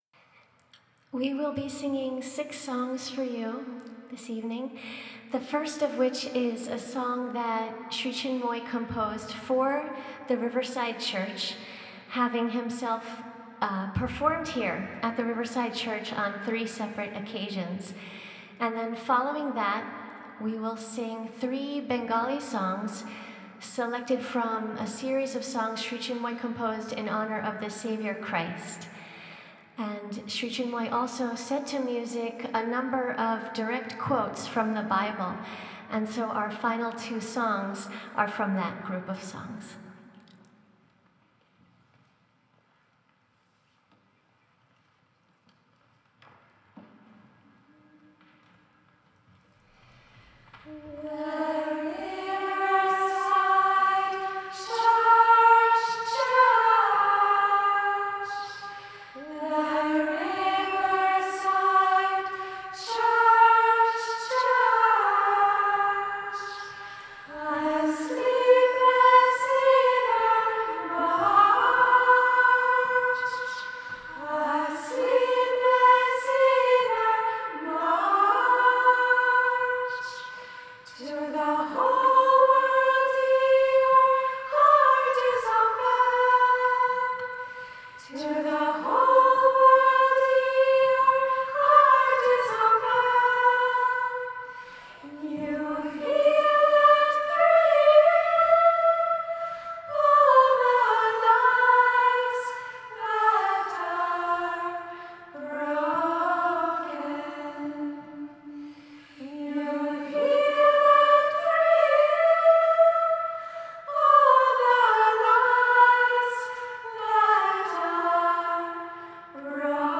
The following selections are from the recent Songs of the Soul concert in New York, which took place on April, 2009 at the famous Riverside Church.
The Gandharva Loka Orchestra is a 75-member international orchestra, featuring star musicians and singers from over 15 countries, working as a seamless ensemble.
We hope you enjoy the sweetness and power, the height and depth of these soul-stirring melodies by Sri Chinmoy, offered by an international cast of his meditation students.